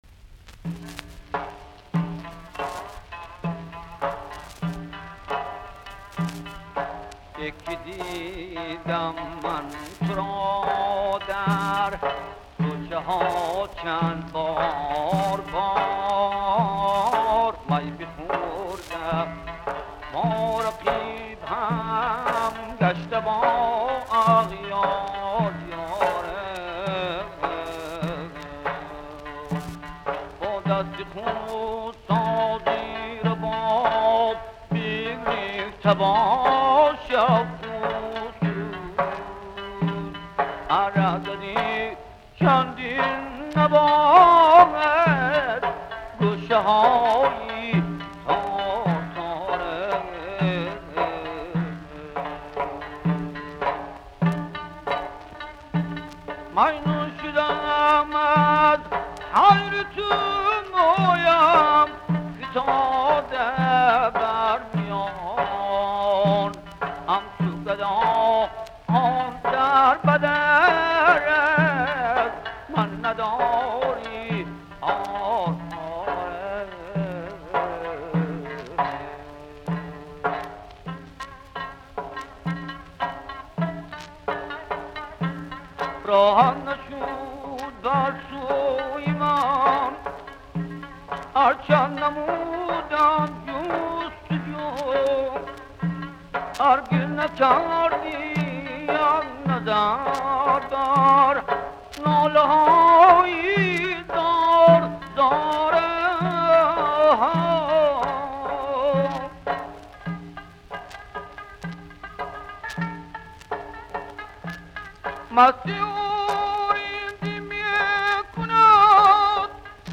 From Bukhara. Folk song with tar, tambur, doira.